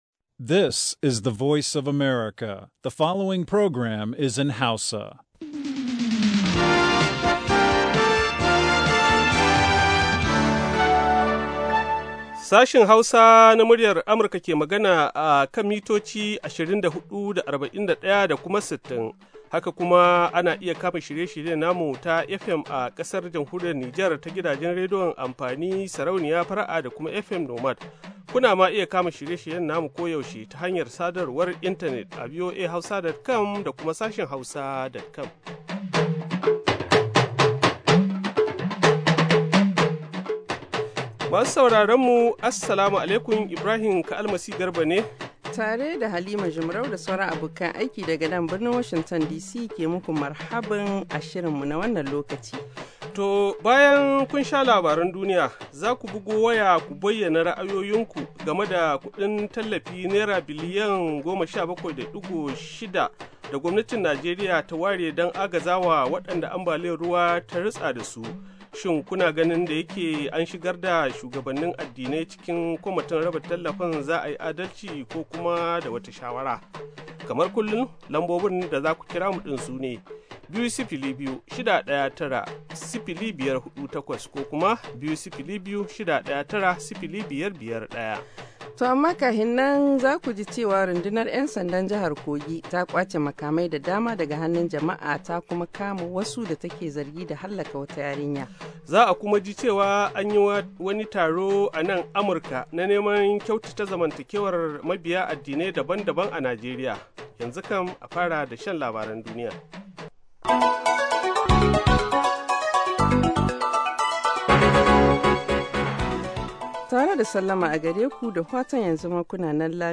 A shirin namu na karfe 9 da rabi agogon Najeriya da Nijar, zaku ji labarai na yadda duniyar ta yini da rahotanni da dumi-duminsu, sannan mu kan bude muku layuka domin ku bugo ku bayyana mana ra’ayoyinku kan batutuwan da suka fi muhimmanci a wannan rana, ko kuma wadanda ke ci muku tuwo a kwarya.